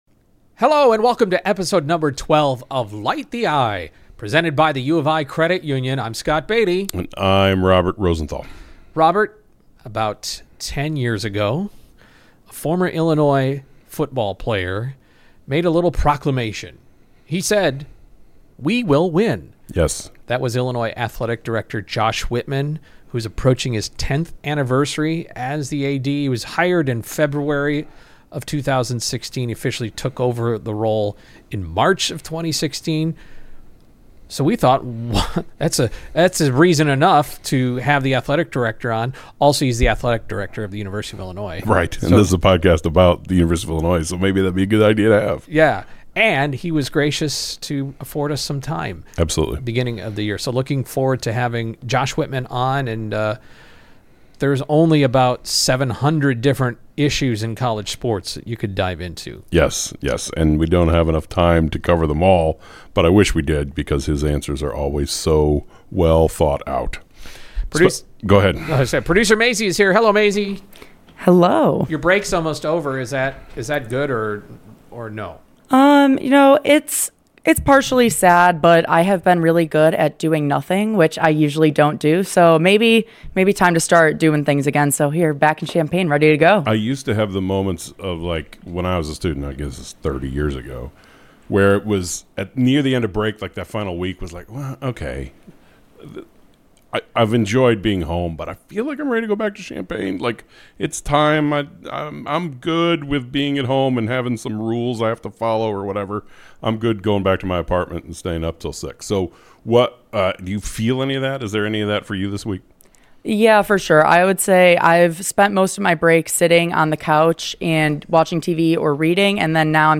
for a wide-ranging conversation.